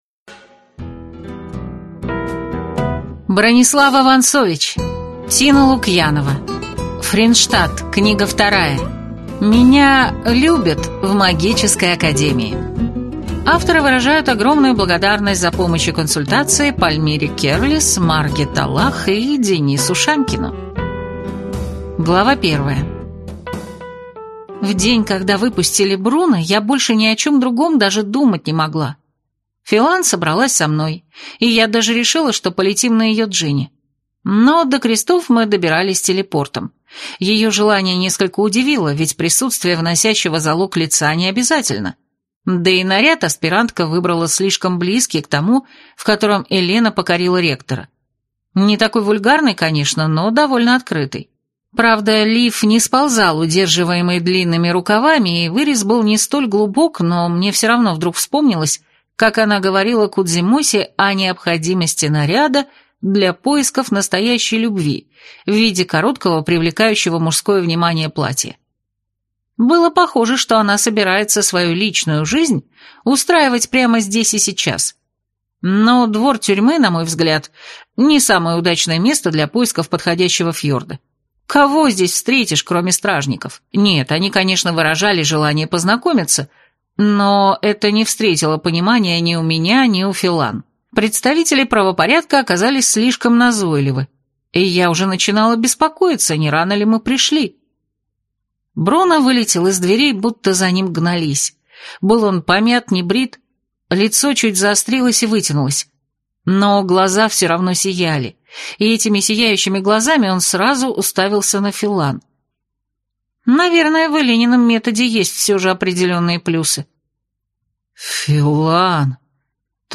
Аудиокнига Меня любят в магической академии | Библиотека аудиокниг